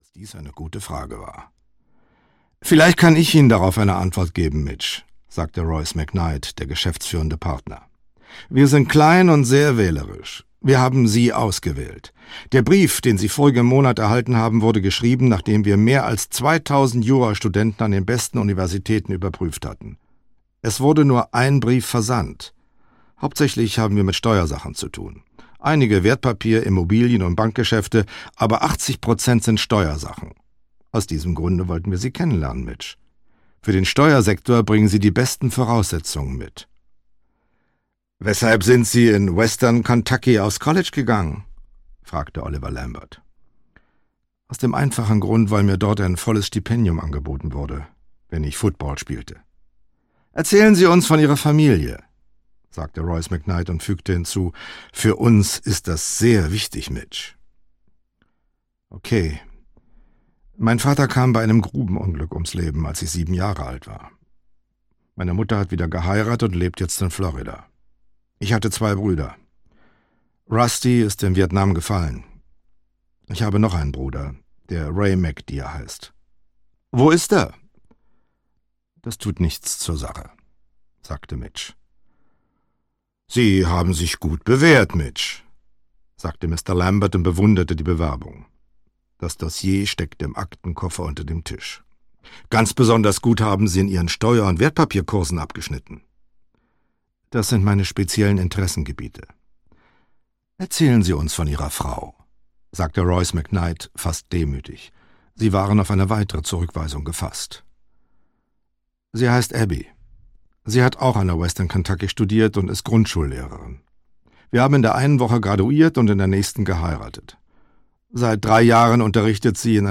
Audio kniha
• InterpretCharles Brauer